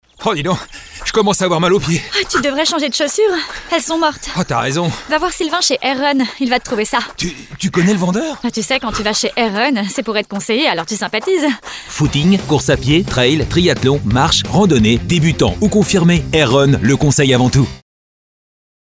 Spot radio Vaxibus Skyrock
Voix off homme pour vos pubs radio